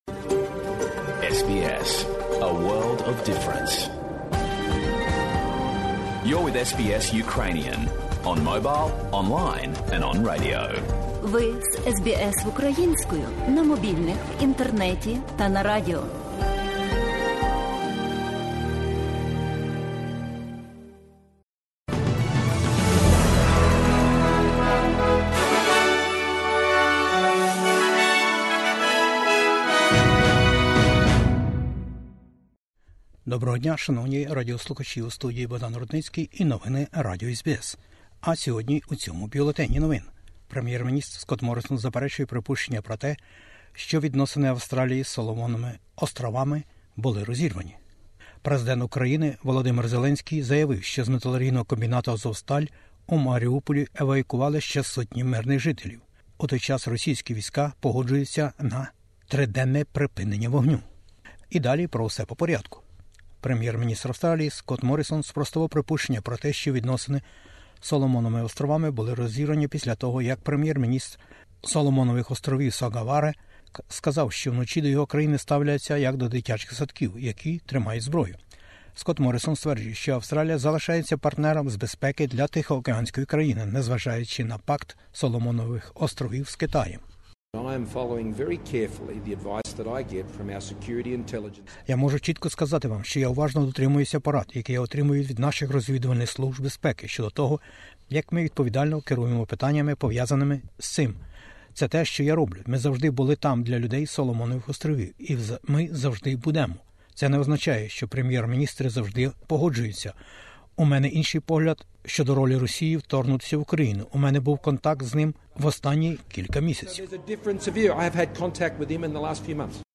Бюлетень SBS новин українською мовою. Федеральні вибори-2022 і політичні перипетії навколо Соломонових островів. Президент України про евакуацію із Маріуполя та міжнародну підтримку України. Резервний Банк Австралії про підвищення відсотків.